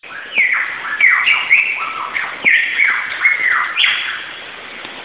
Bird of the week: Superb Lyrebird
They mimic a huge variety of sounds, but are particularly fond of putting whip-bird songs into their repertoire.
recording of a Lyrebird (taken on my phone) that I use it as my ringtone!
lyrebird.mp3